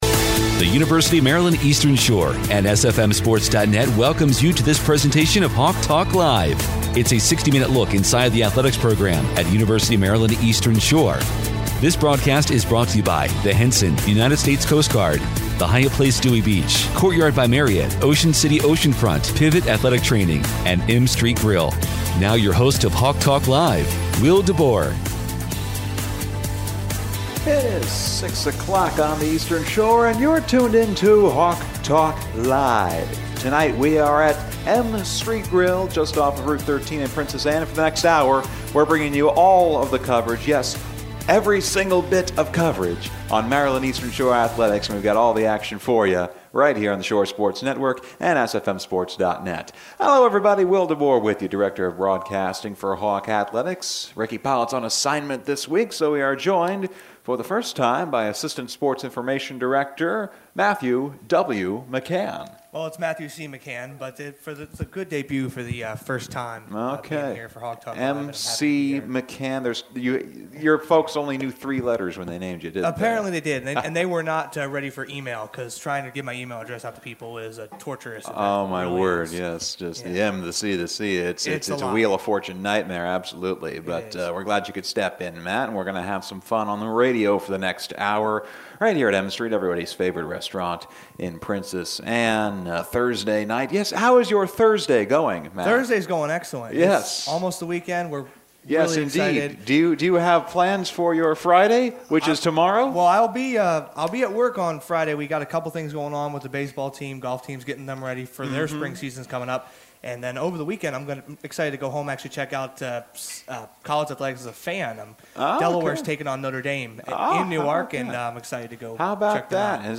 Bowling and Indoor Track from M-Street Grille in Princess Anne.